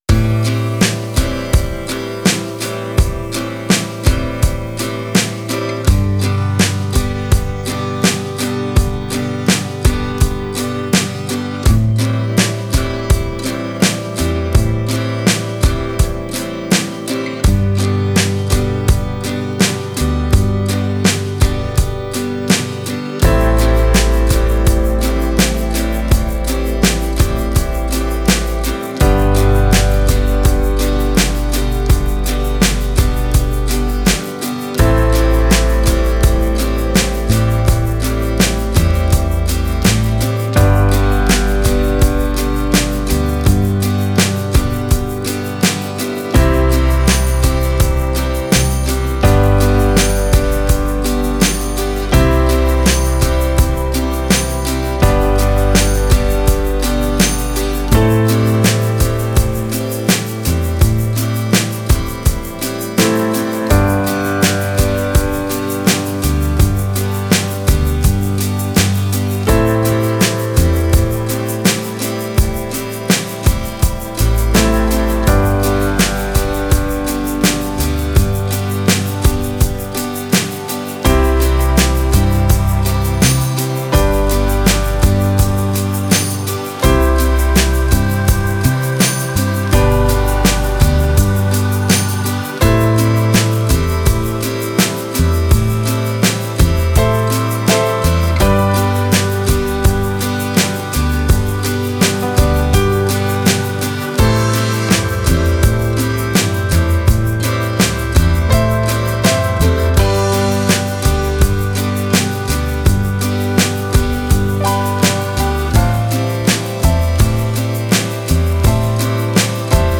Genres: Country and local